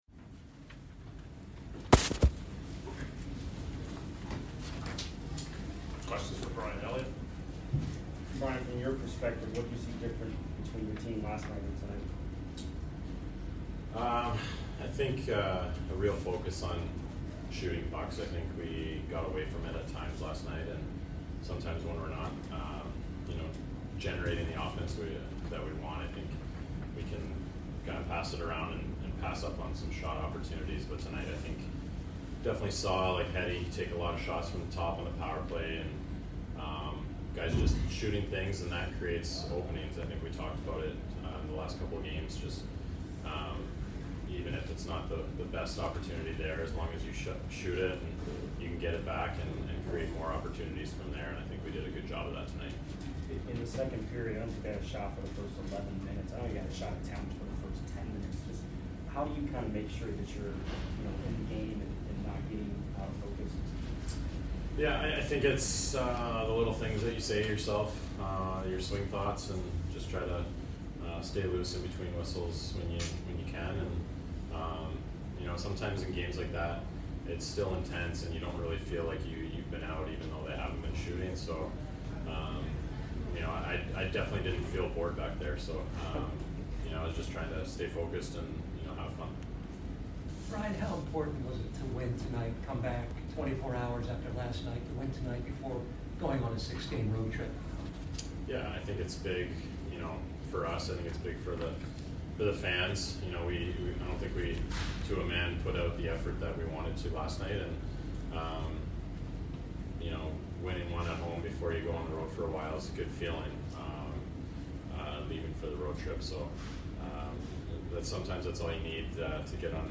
Brian Elliott Post Game Vs DET 3 - 4-2022